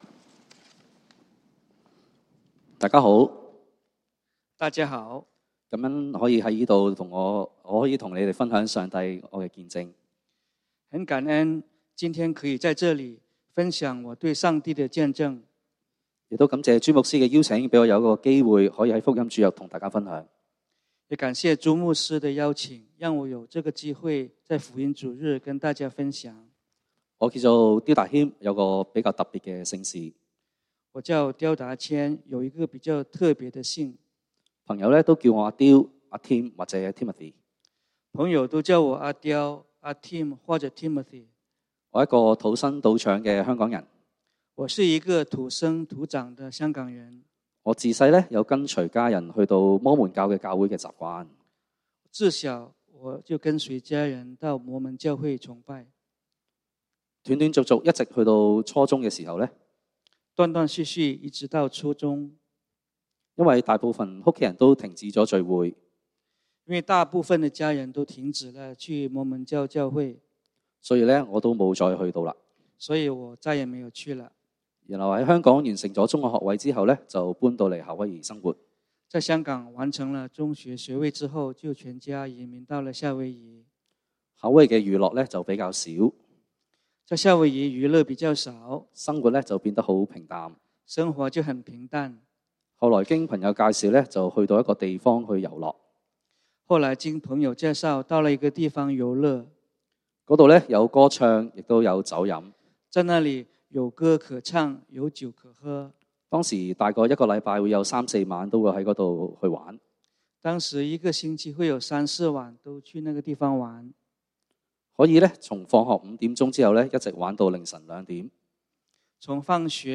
福音主日 講道經文：《馬可福音》Mark 12:28-31